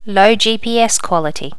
Additional sounds, some clean up but still need to do click removal on the majority.
low gps quality.wav